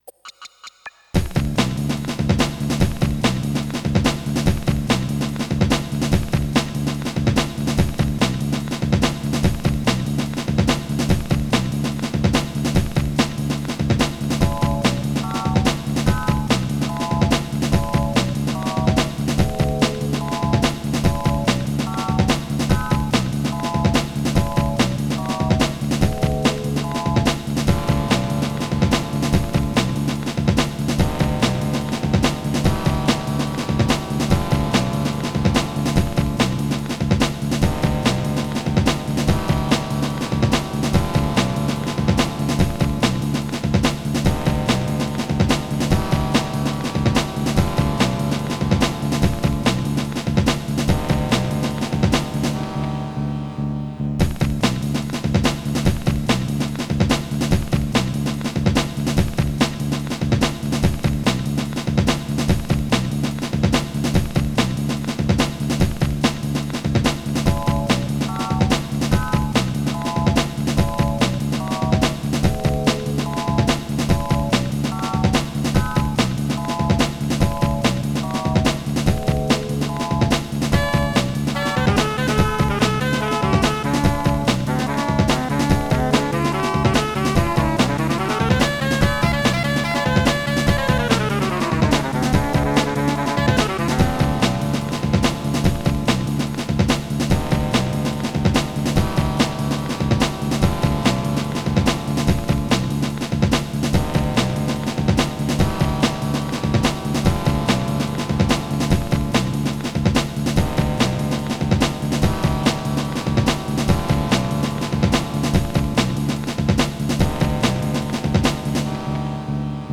Home > Music > Rock > Running > Chasing > Restless